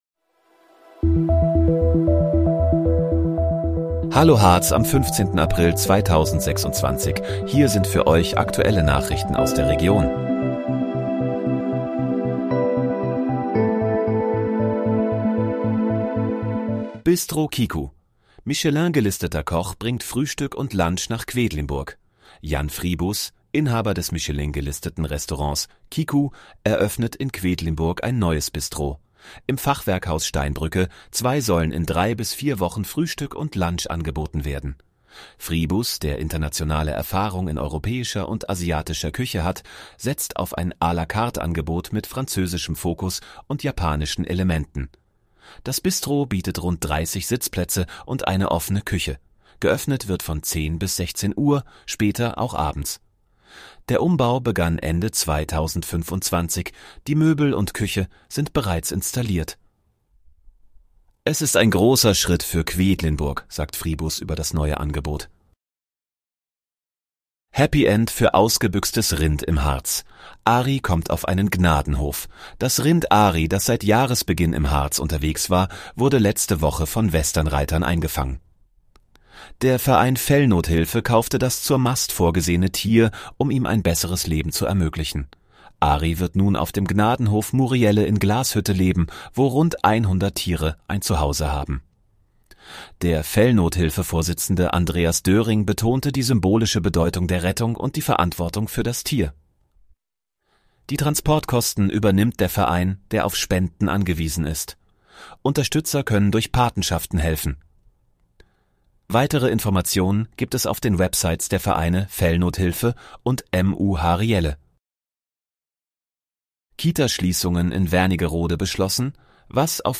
Hallo, Harz: Aktuelle Nachrichten vom 15.04.2026, erstellt mit KI-Unterstützung